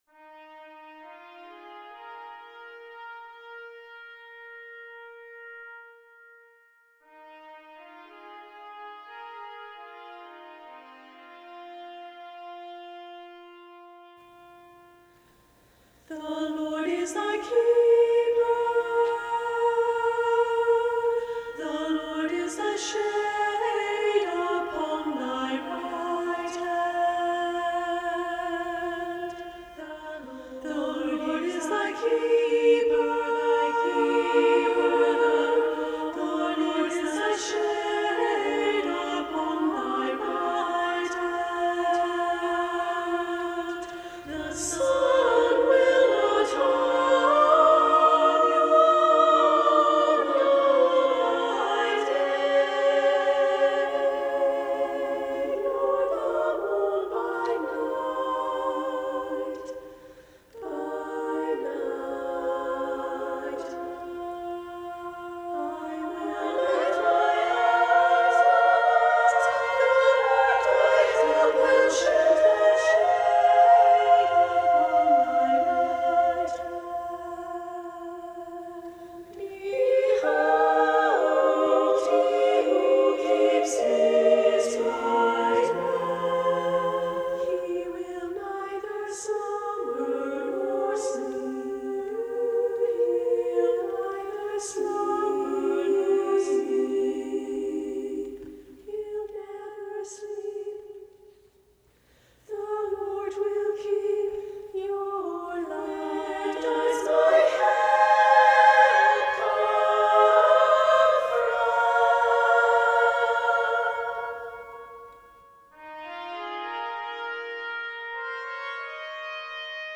SSAA with Bb Trumpet